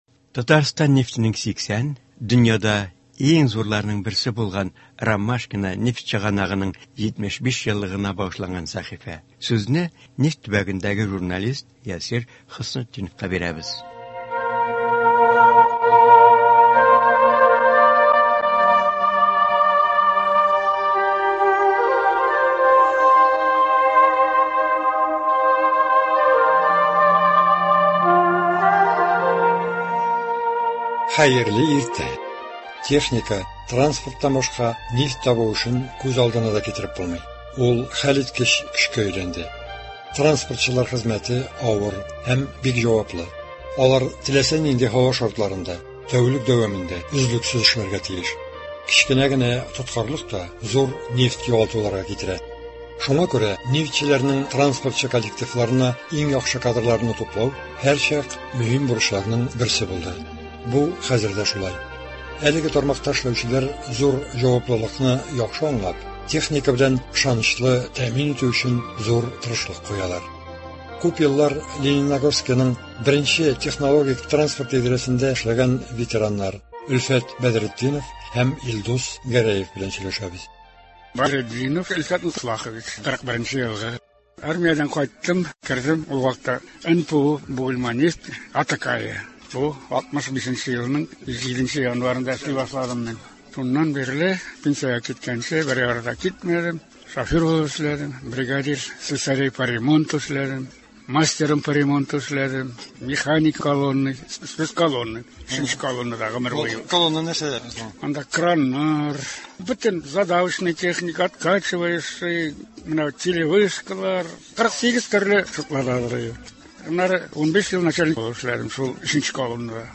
Техника, транспорттан башка нефть табу эшен күз алдына да китереп булмый. Транспортчылар теләсә нинди һава шартларында, тәүлек буе өзлексез тырыш хезмәттә. Лениногорскидан шушы тармакта озак еллар эшләгән хезмәт ветераннары белән әңгәмә.